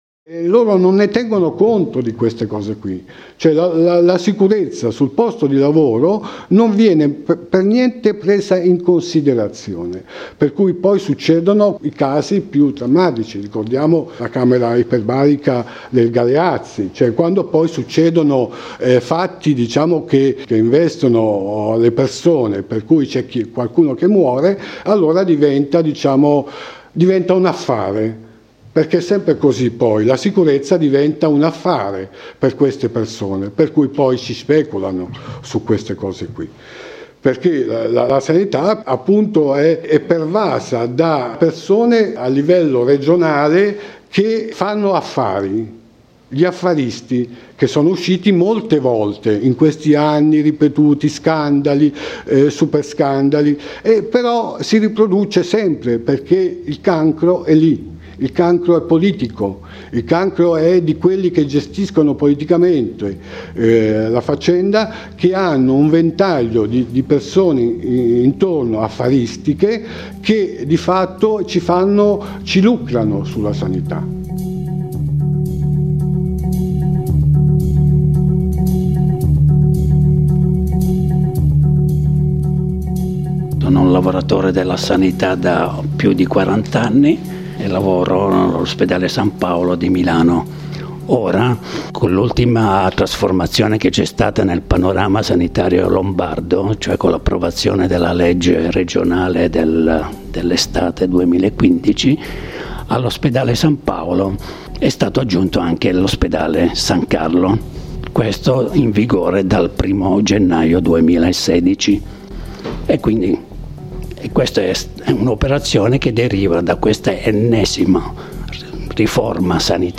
Di questo e di altro abbiamo parlato insieme con quattro lavoratori della Sanità in Lombardia Scarica il podcast Twitter Facebook Whatsapp Telegram Reddit